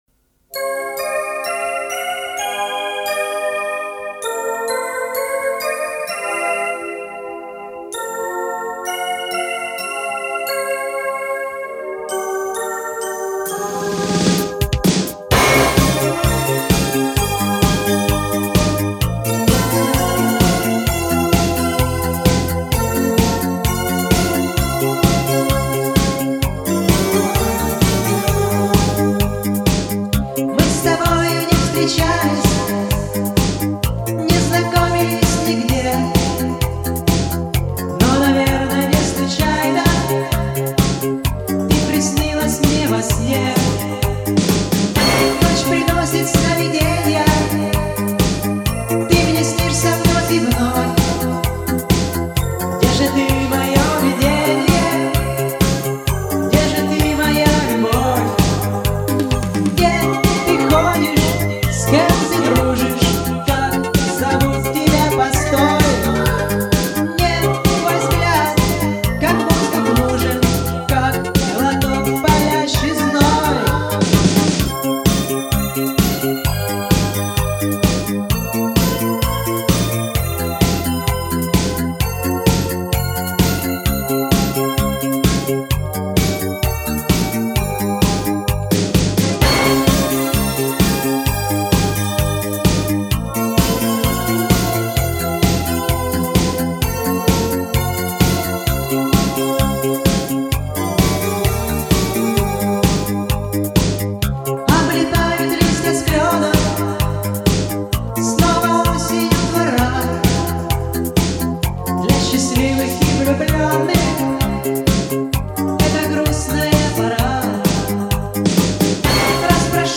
вот 2 варианта с усилением низких.